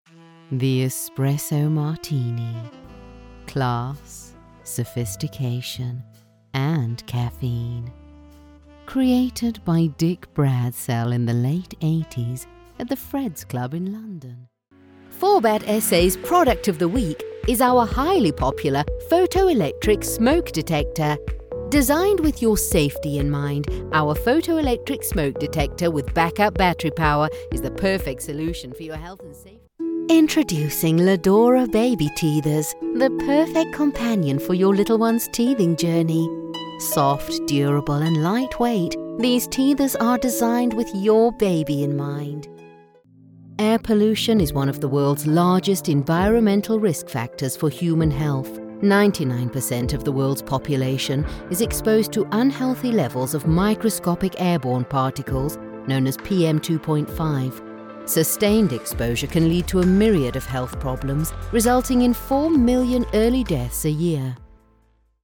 Mi acento inglés neutro funciona bien en los mercados internacionales.
Mi voz es natural y amigable, pero resonante y autoritaria. A mis clientes también les encanta mi voz seductora.
Micrófono Audio Technica AT2020